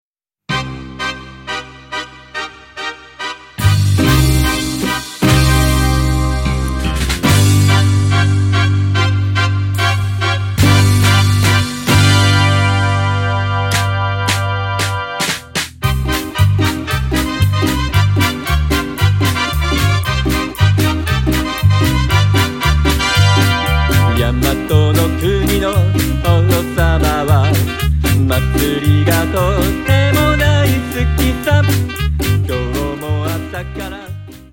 新潟長岡アマテラススタジオにて収録